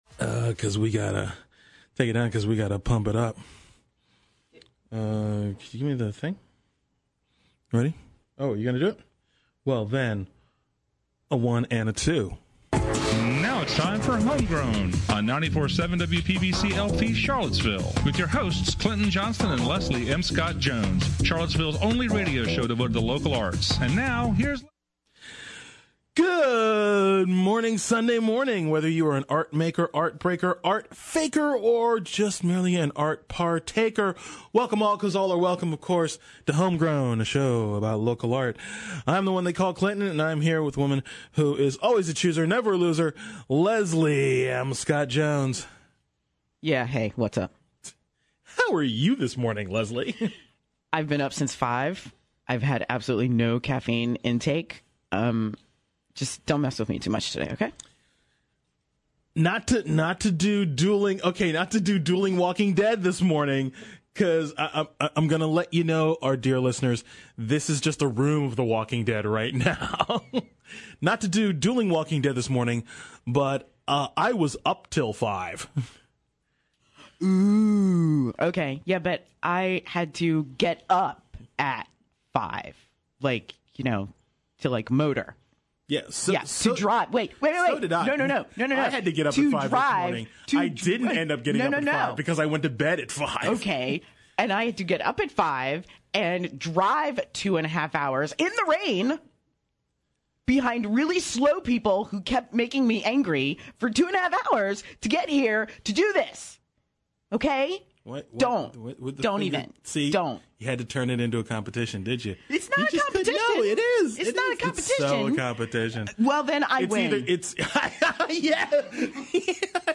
As always, it’s artists talking art on Home Grown: Your Show about Local Art .